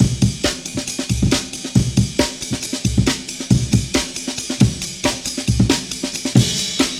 The tempo is 137 BPM.